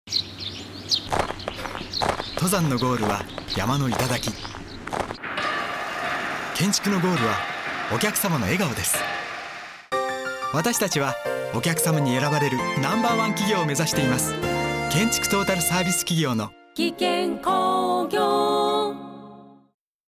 この度ご縁があって、8月2日からFBCラジオさんで弊社のコマーシャルが流れることになりました。
giken_radio.mp3